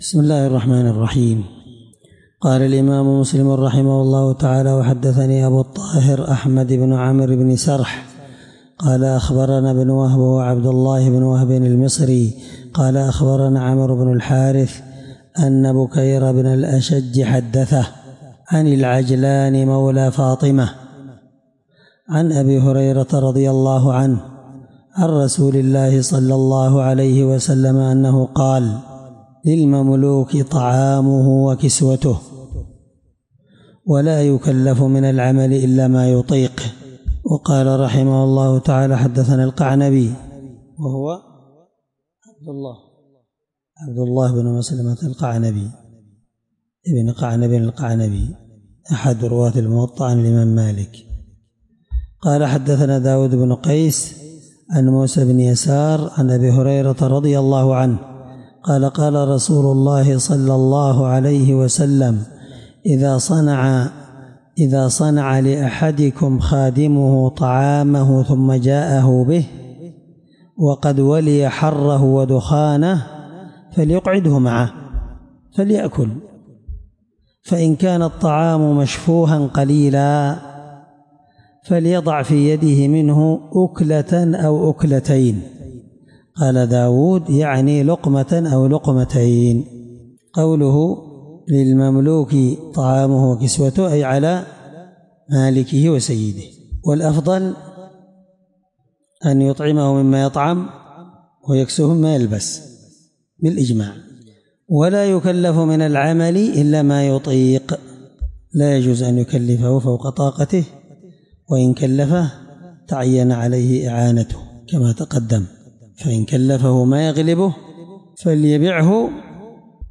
الدرس14من شرح كتاب الأيمان حديث رقم(1662-1663) من صحيح مسلم